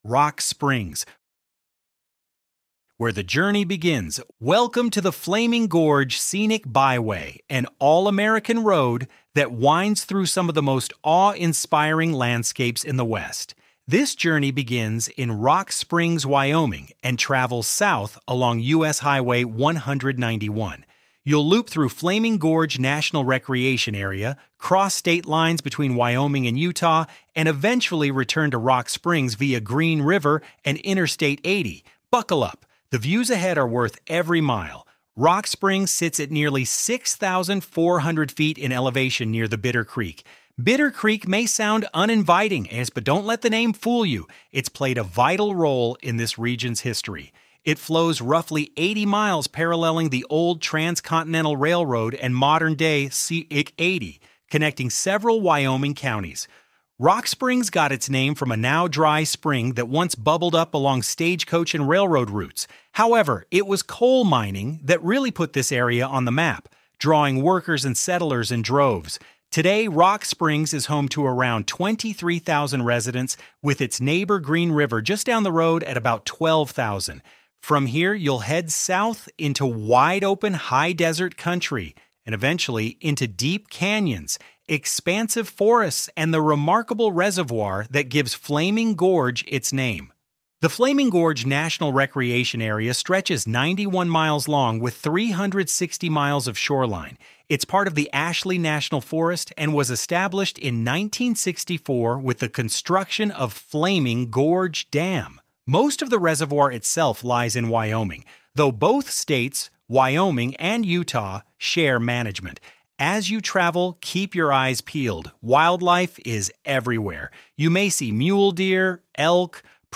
Audio Guided Tour Listen to the full Flaming Gorge Audio Tour Here Map: View or download a map of the Flaming Gorge Scenic Byway Disclaimer: This is a true “off the grid experience” and cell service is limited!